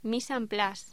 Locución: Mise en place
voz